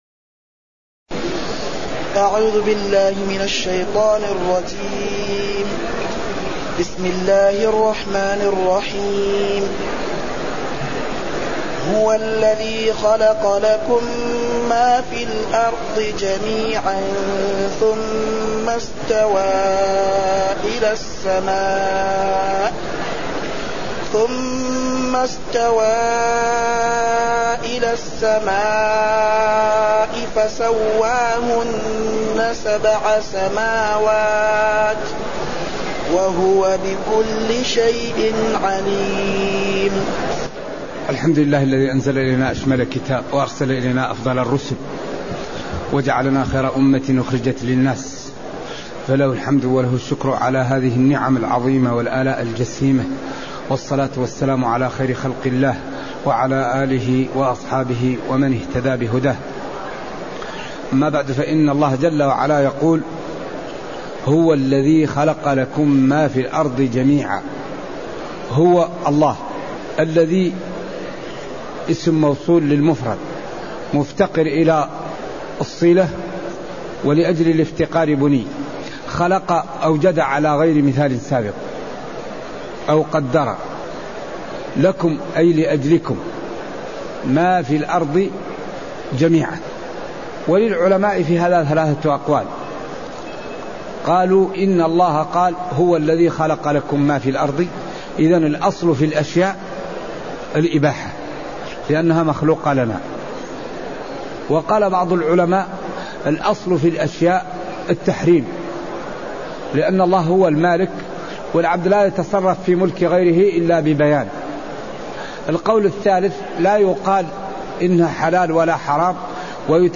تاريخ النشر ٢٧ ذو الحجة ١٤٢٧ هـ المكان: المسجد النبوي الشيخ